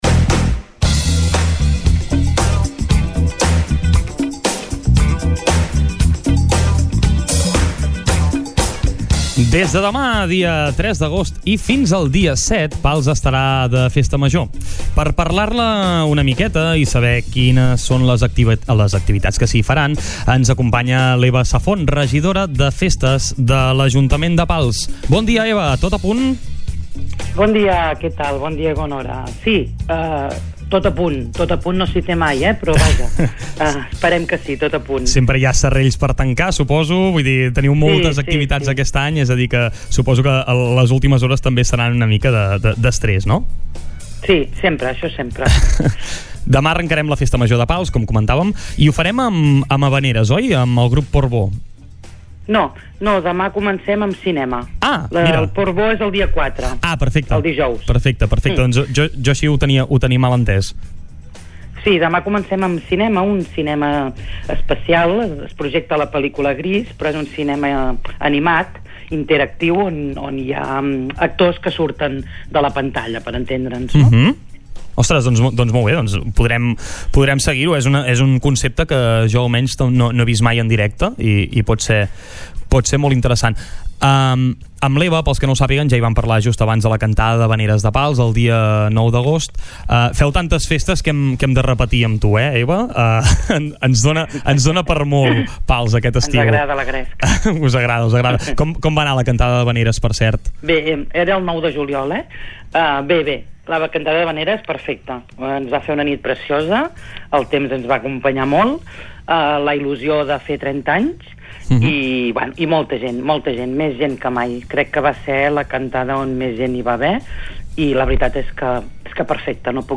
Entrevistes SupermatíPalsSupermatí d'Estiu
Per descobrir totes les activitats que s’hi realitzaran, hem tingut avui a la regidora de festes de l’Ajuntament de Pals, Eva Safont, als micròfons del Supermatí d’Estiu.